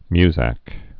(myzăk)